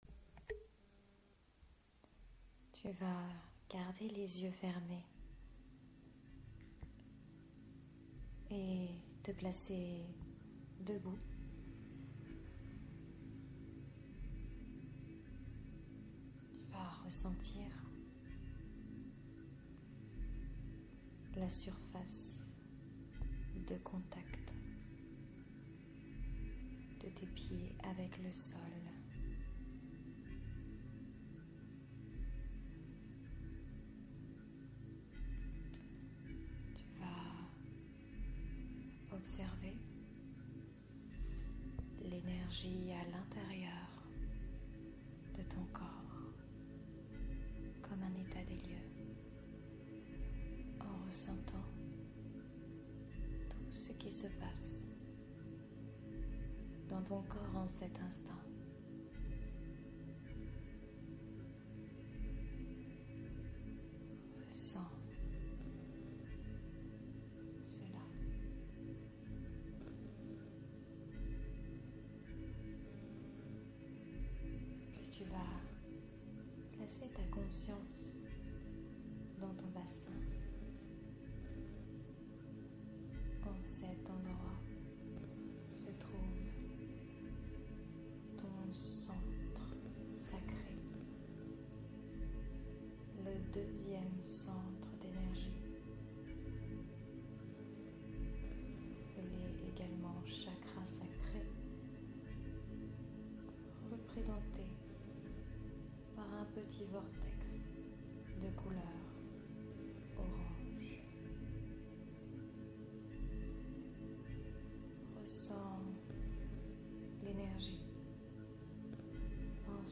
Un soin guidé pour déployer son féminin sacré au delà des mémoires limitantes.
soin-guide-eveil-du-feminin-sacre.mp3